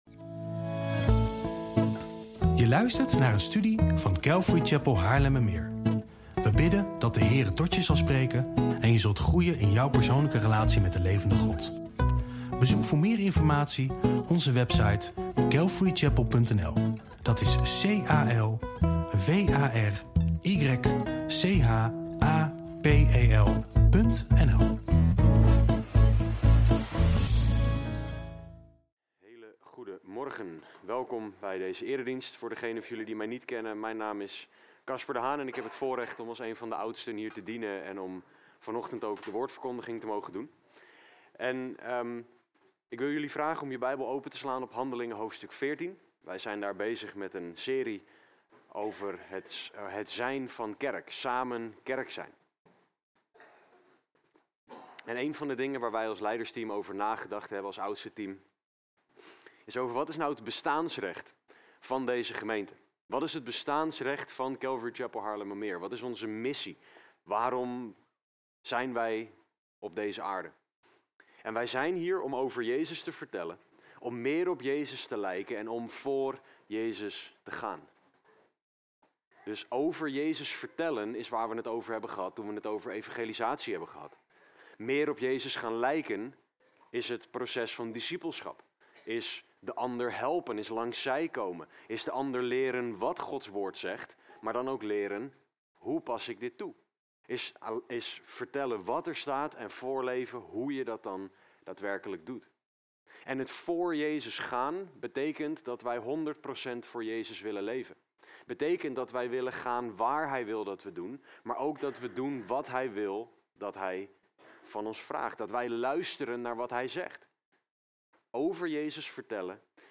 Preek-93-online.mp3